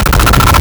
Player_Glitch [2].wav